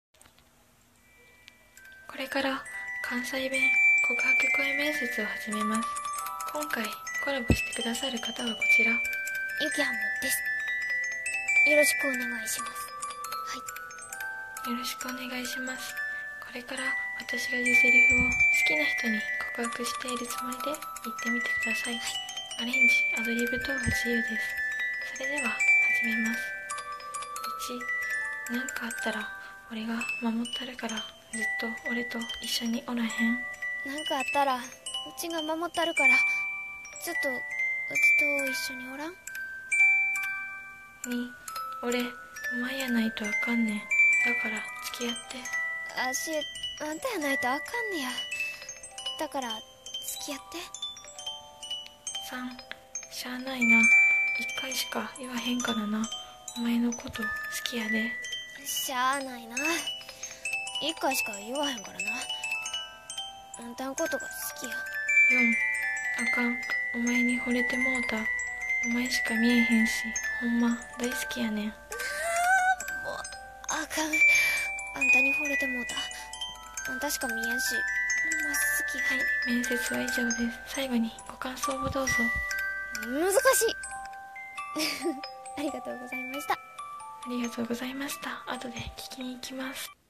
関西弁告白声面接